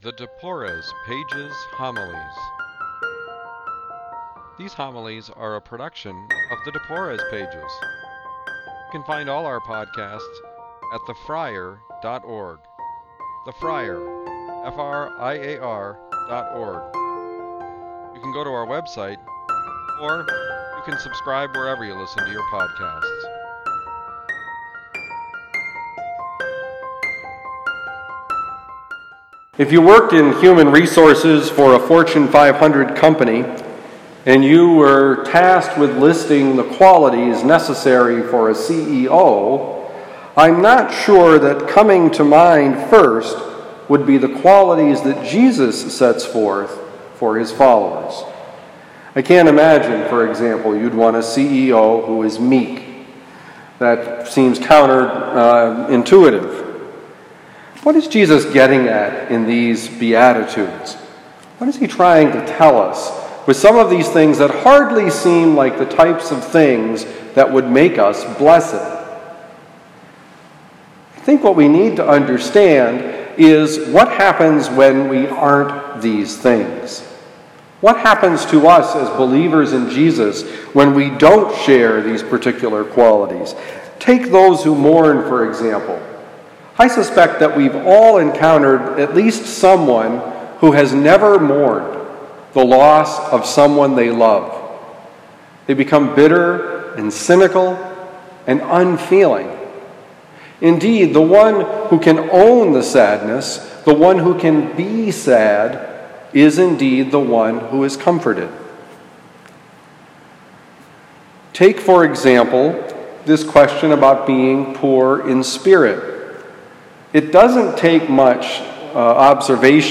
Homily: The World and the anti-Gospel